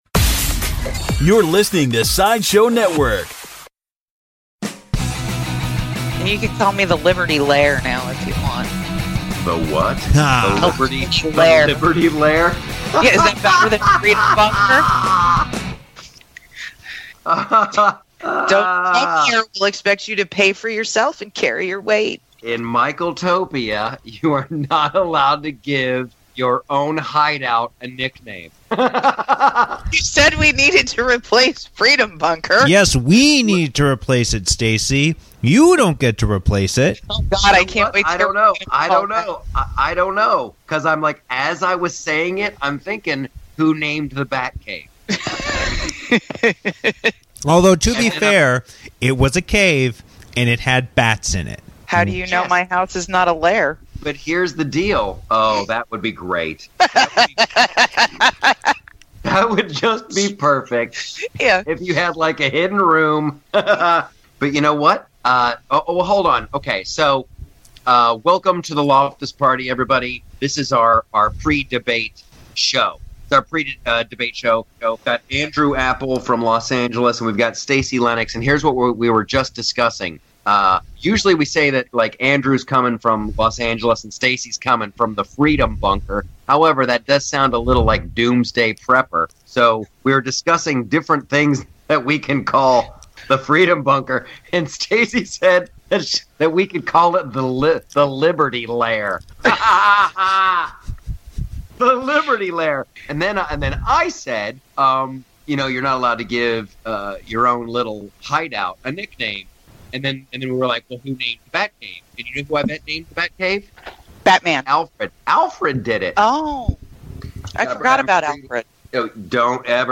Ted Cruz finally comes around to endorsing Donald Trump! Plus special appearances by Michael Caine and Bernie Sanders (impressions) and Team Aniston finally says bye bye to Angelina!